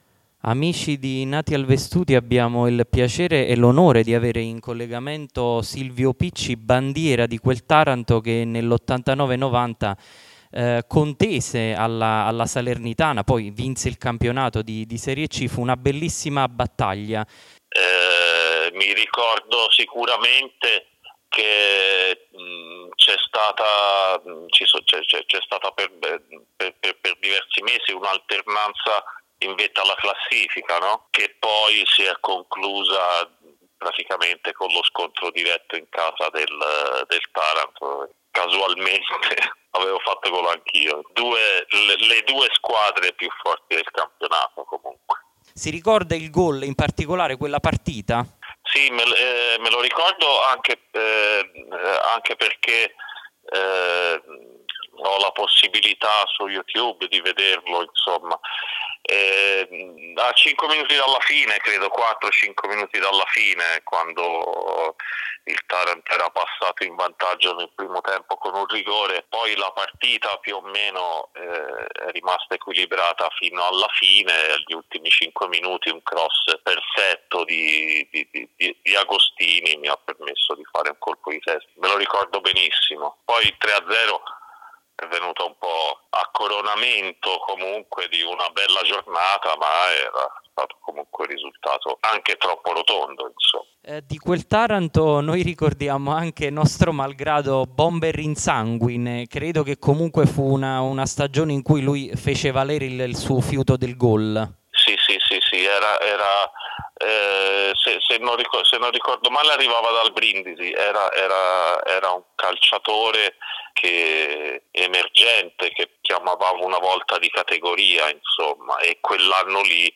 Interviste Lascia un commento